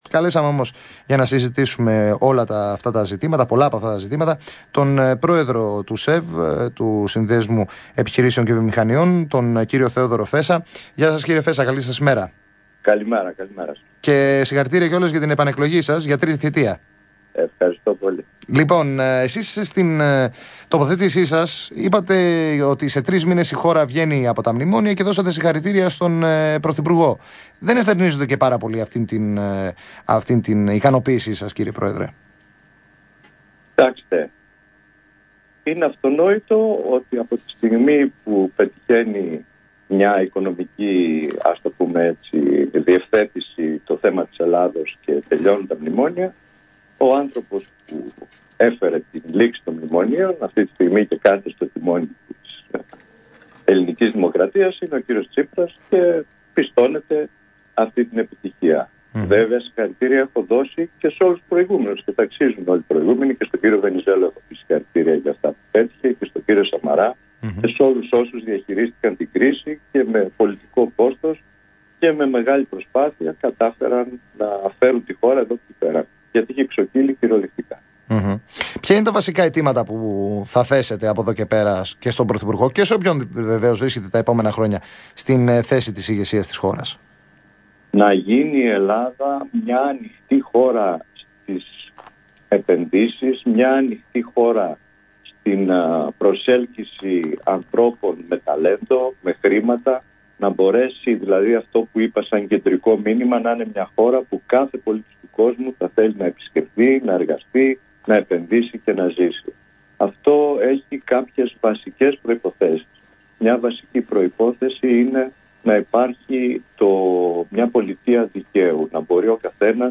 Συνέντευξη
στον Ρ/Σ “ΣΤΟ ΚΟΚΚΙΝΟ”